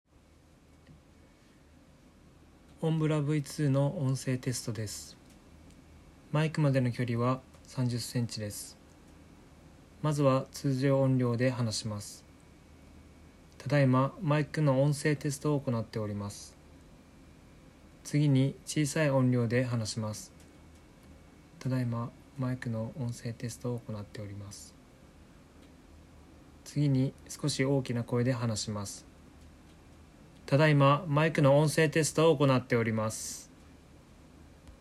以下の「音声テストで読み上げる内容」に記載の通り、顔から30cmに音声録音用のマイクを設置してテストを行いました。
まずはマスクを着用しないで読み上げます。
■検証①（OMBRA v2を装着しないで読み上げる）